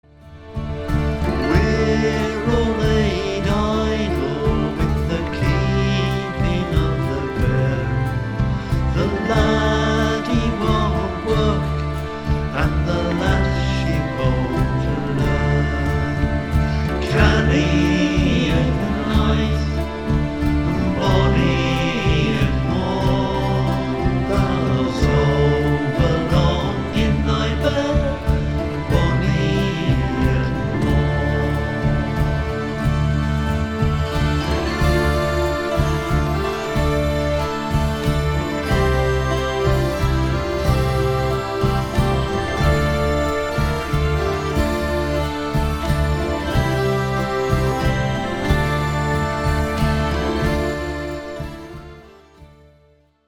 A traditional song from Northumberland.